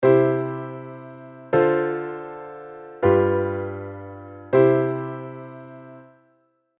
このツー・ファイブからのドミナントモーションの流れをまとめて
スムーズな印象 になりますねっ。